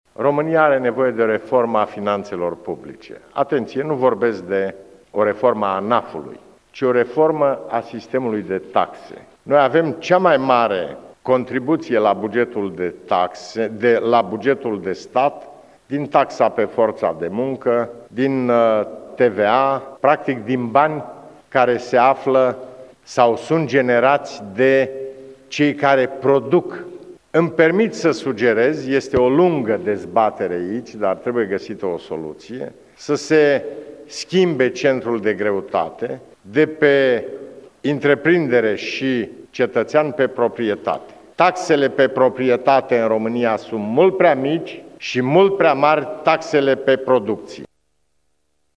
Afirmaţia a fost făcută, în această seară, de preşedintele Traian Băsescu, la bilanţul celor două mandate ca şef al statului.